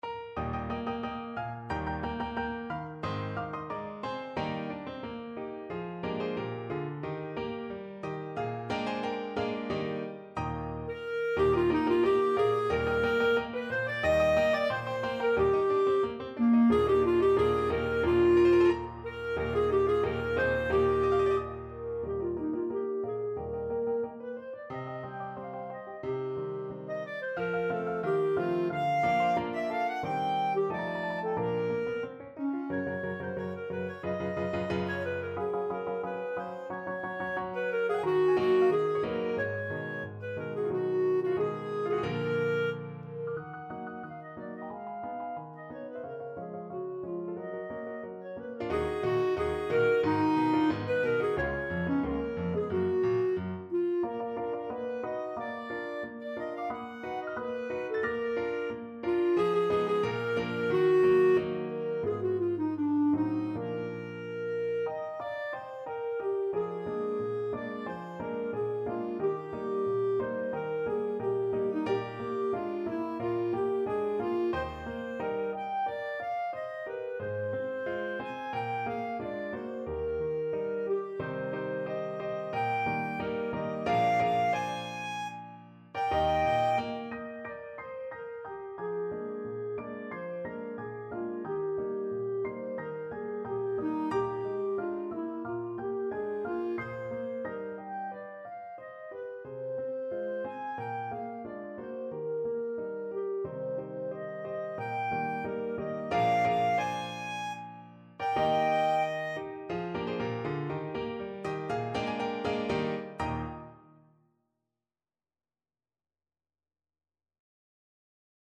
2/4 (View more 2/4 Music)
Allegretto =90